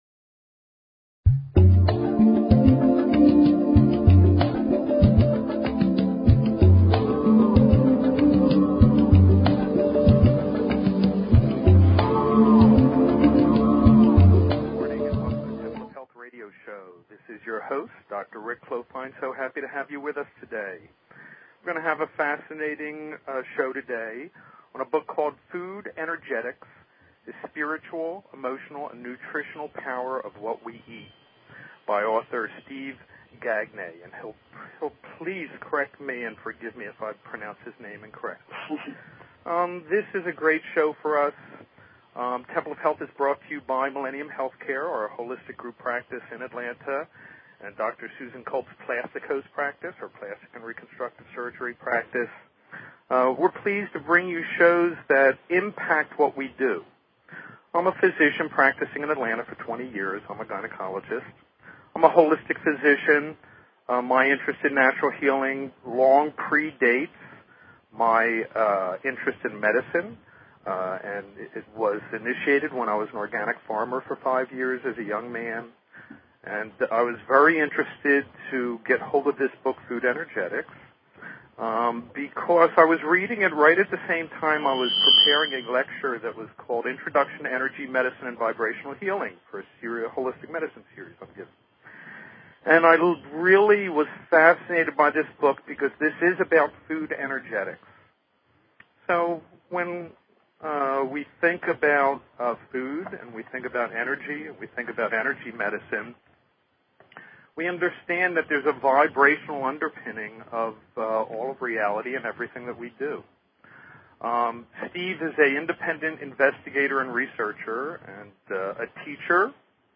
Talk Show Episode, Audio Podcast, Temple_of_Health_Radio_Show and Courtesy of BBS Radio on , show guests , about , categorized as